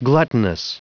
Prononciation du mot gluttonous en anglais (fichier audio)
Prononciation du mot : gluttonous